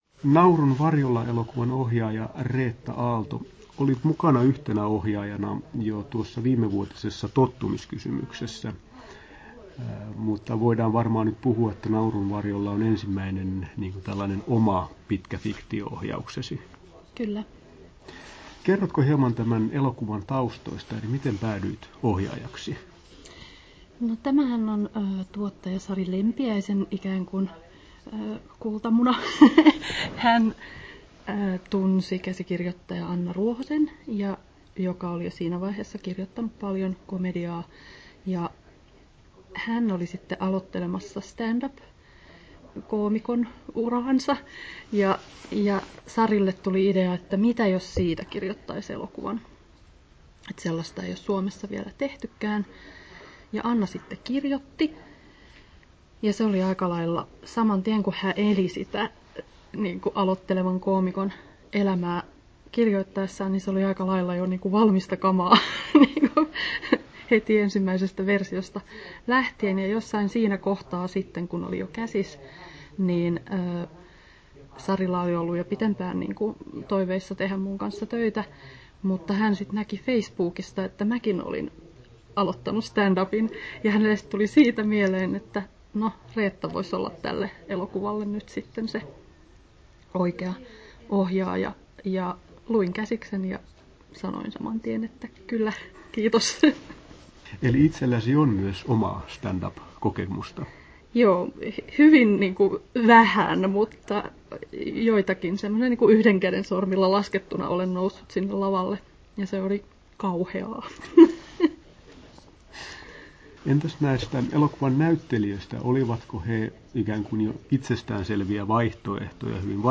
Haastattelut
Turku Toimittaja